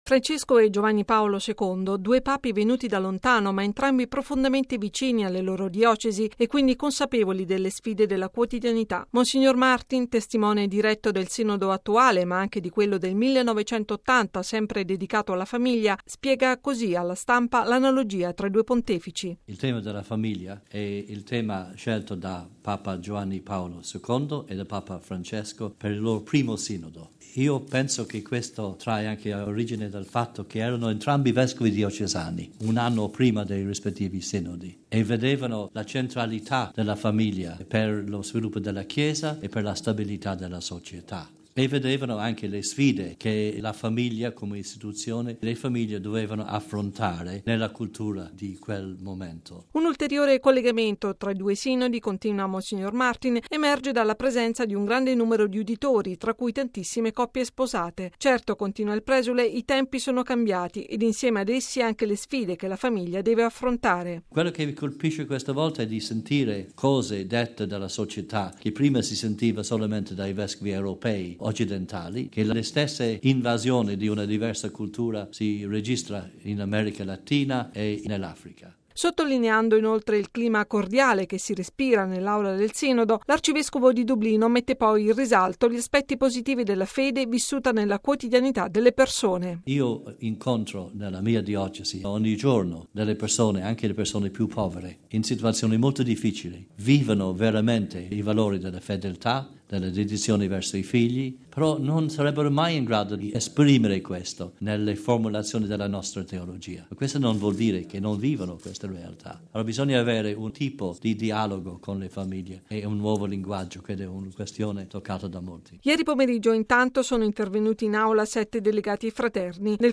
Papa Francesco, come Giovanni Paolo II, ha scelto il tema della famiglia per il suo primo Sinodo: è quanto messo in risalto da mons. Diarmuid Martin, arcivescovo di Dublino, in un briefing con i giornalisti svoltosi oggi nella Sala Stampa vaticana.